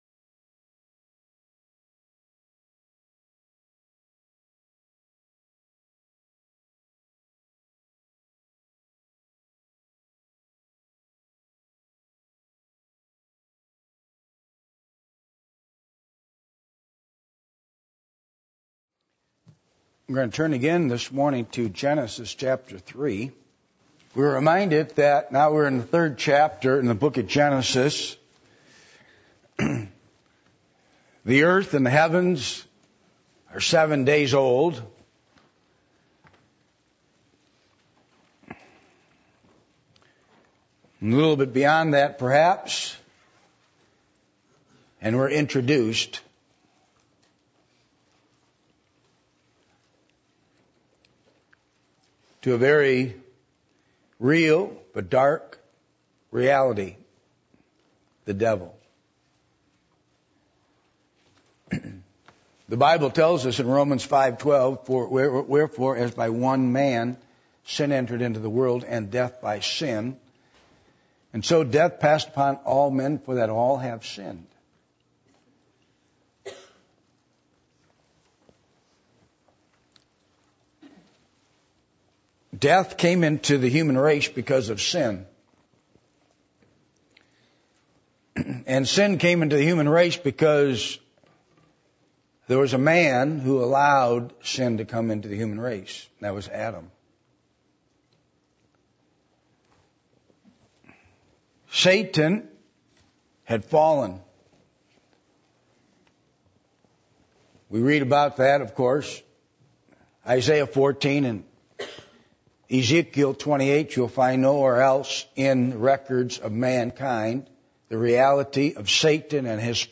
Passage: Genesis 3:1-4:1 Service Type: Sunday Morning %todo_render% « One Of The Secrets Of A Godly Seed Lessons On Bitterness From Joseph’s Family